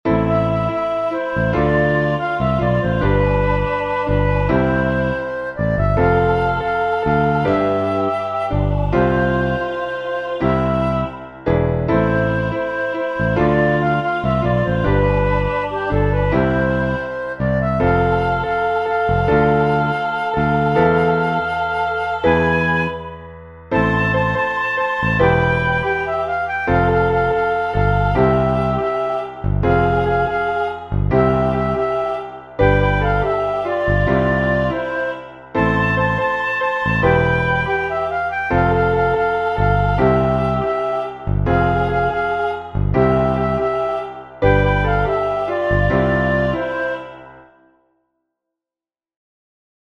χορωδία, φλάουτο, μπάσο και συγχορδίες, Cmaj